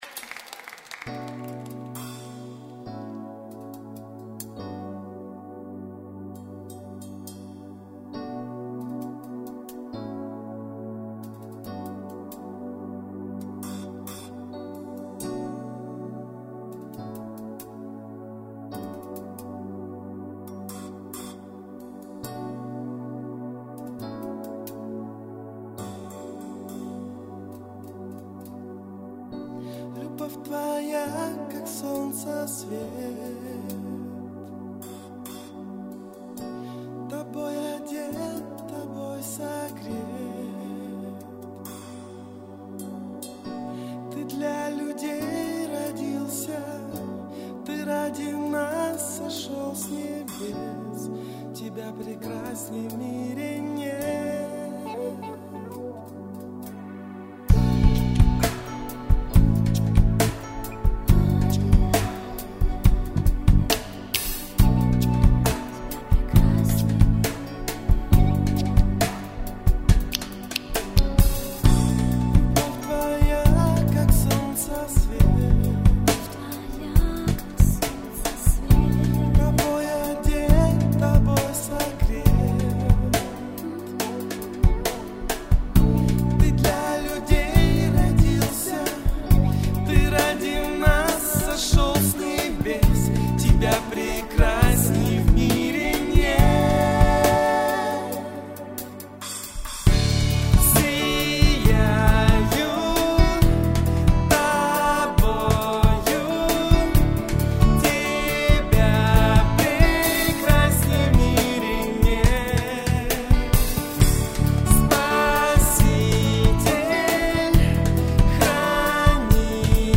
Категория: Поклонение